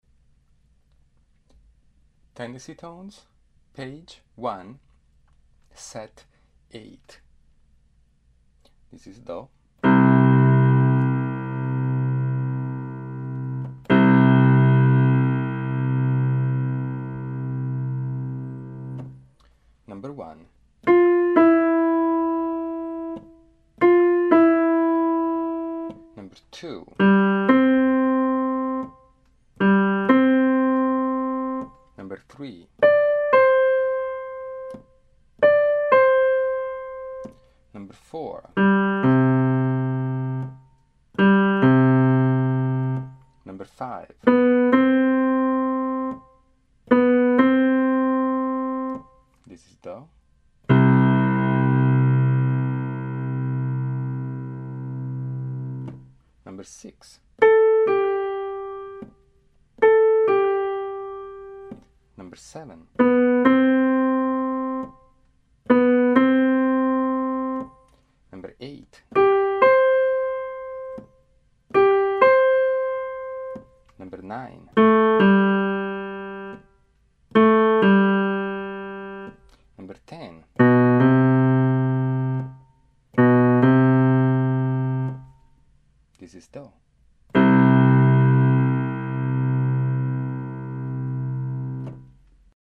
111 tendency tones
The tonic will be played and should be remembered throughout a series of 5 tendency tones. At the end of the first five, the tonic will be played again for the next 5 tendency tones. They will be played 2 times each and in various octaves; if you need extra time you can pause the playback at the end of each tendency tone.